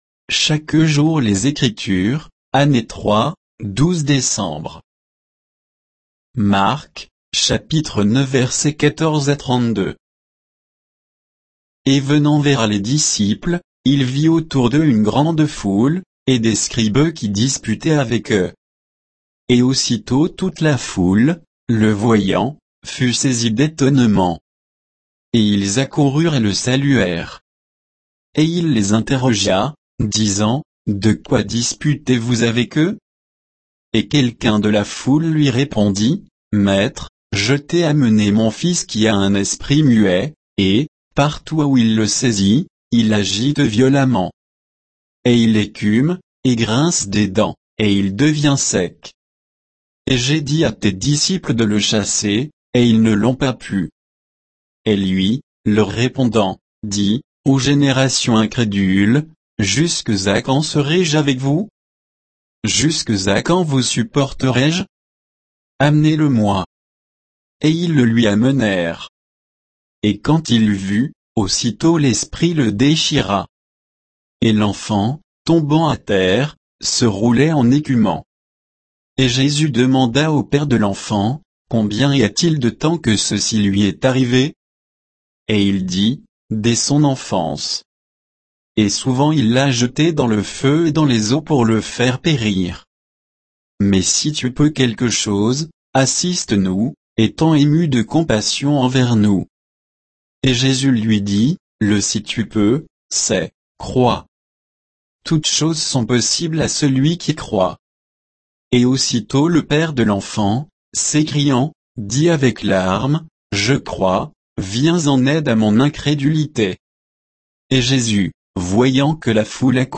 Méditation quoditienne de Chaque jour les Écritures sur Marc 9